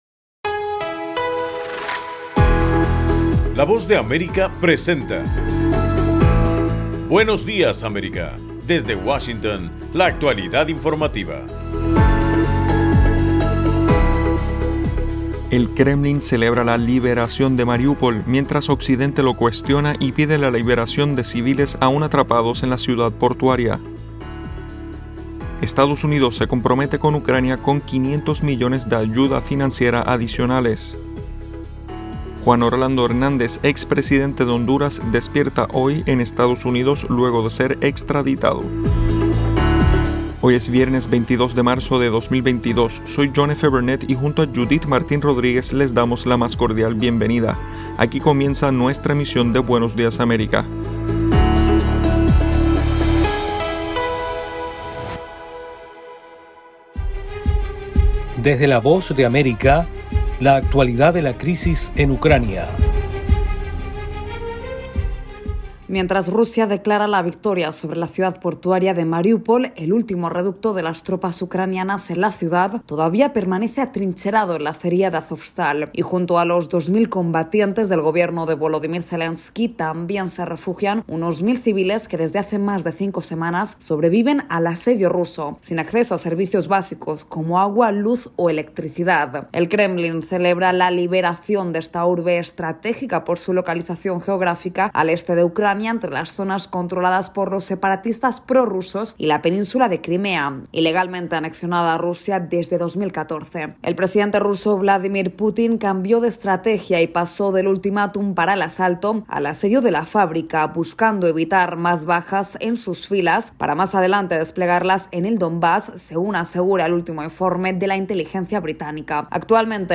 En el programa de hoy, 22 de abril, autoridades de Mariúpol solicitan la “evacuación total” mientras desde Rusia celebran el éxito de sus tropas en la “liberación” de la ciudad. Esta y otras noticias de Estados Unidos y América Latina en Buenos Días América, un programa de la Voz de América.